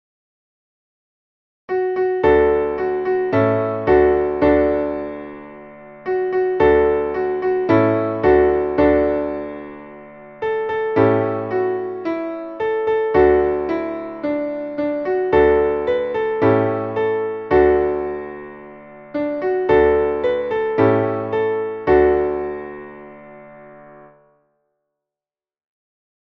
Traditionelles Neujahrslied